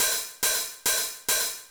K-1 Hi Hat.wav